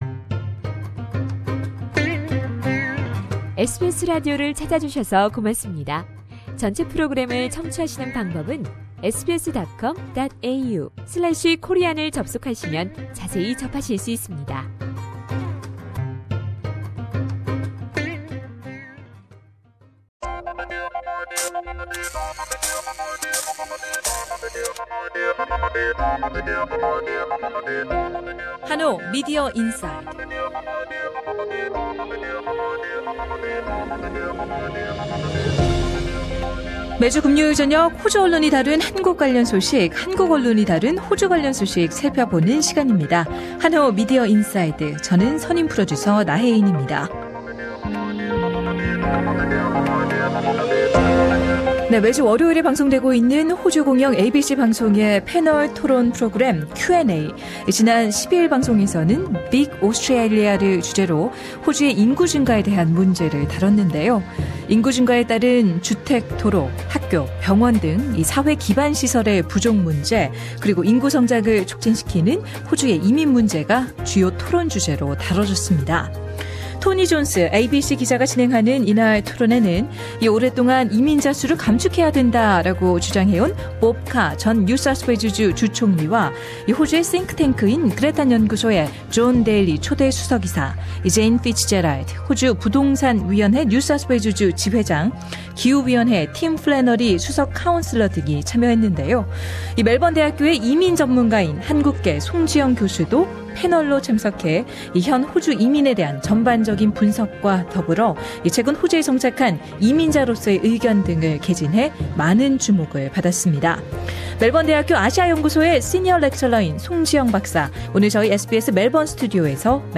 [대담]